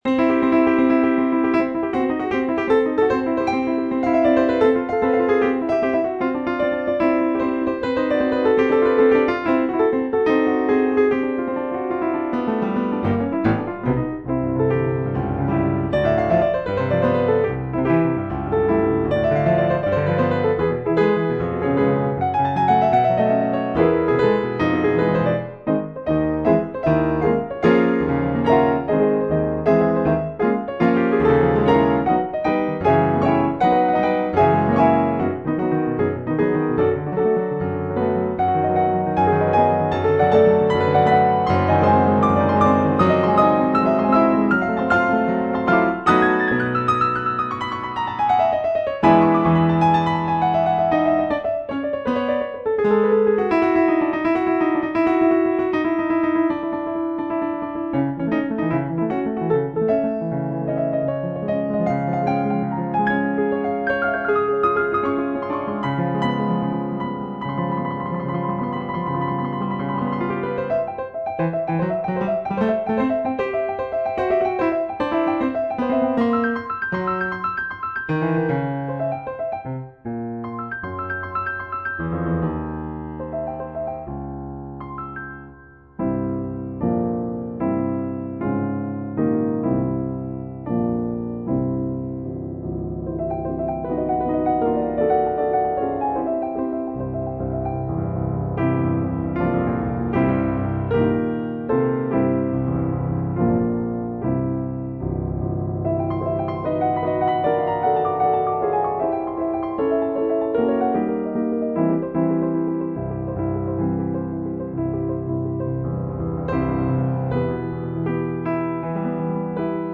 Here are recordings (mp3 files) in which I play eight of my piano compositions.
The quiet middle section leads back into the brilliant, affirmative mood of the opening.